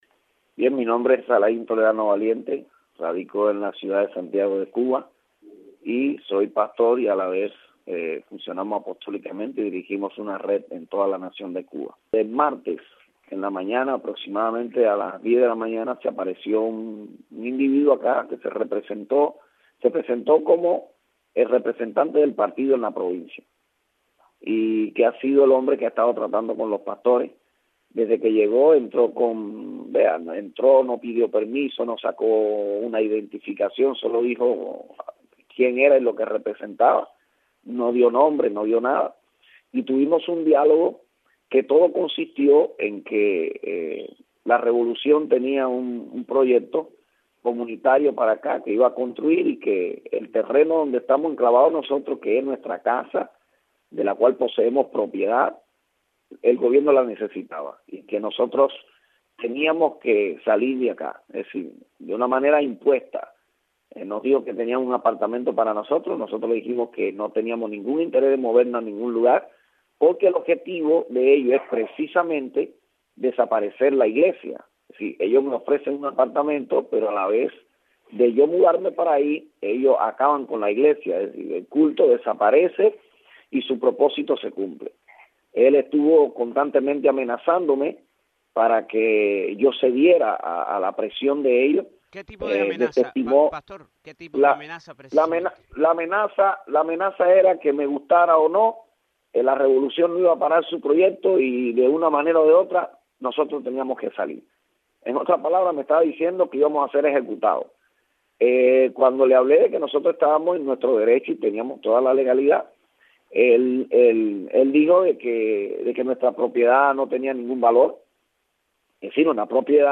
Testimonio